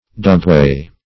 Dugway \Dug"way`\, n.